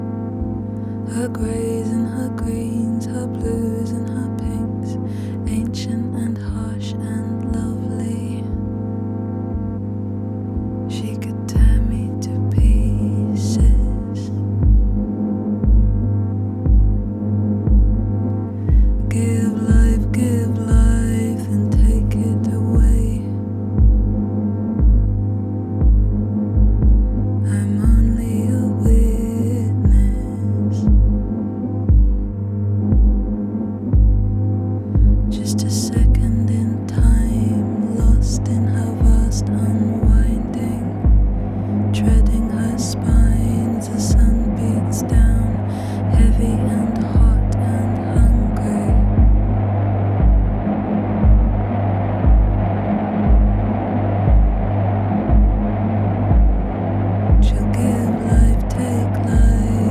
Sensual, com uma energia que conhece o seu destino.
Lindíssimo.